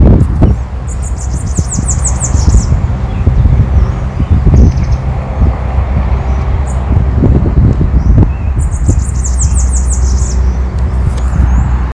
Bananaquit (Coereba flaveola)
Detailed location: Parque de la ciudad
Condition: Wild
Certainty: Photographed, Recorded vocal